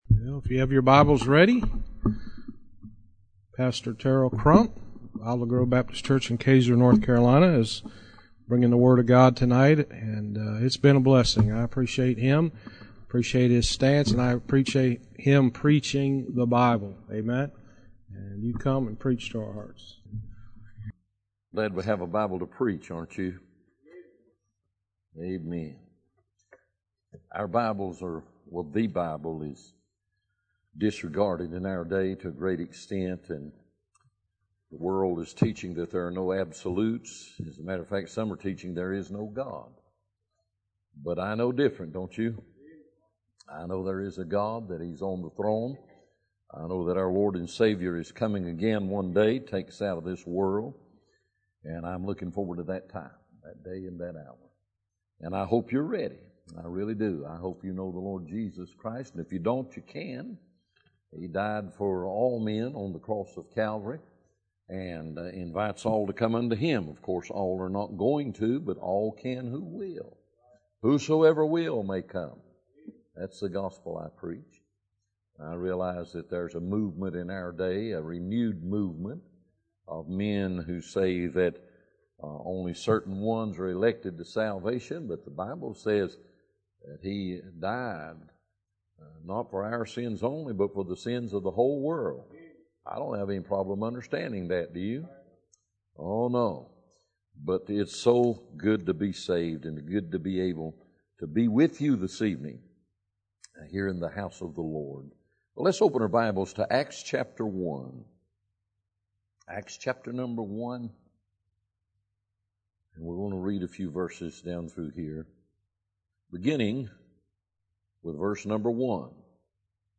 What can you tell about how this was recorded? Fall Revival – Power